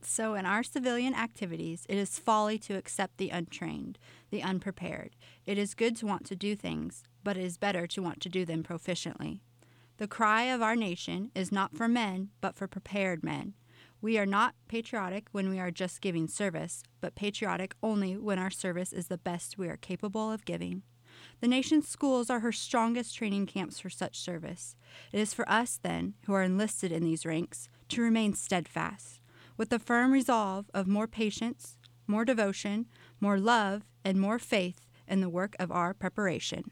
Recorded partial reading of an article entitled "That Still Small Voice" by Ethel Whitcomb published in March 1918 issue of the Normal School Recorder